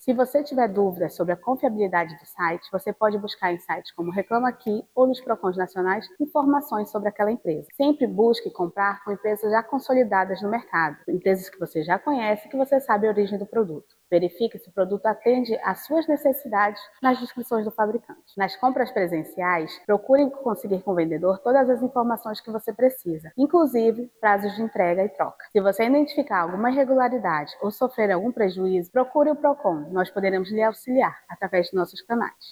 SONORA-2-ORIENTACAO-PROCON-.wav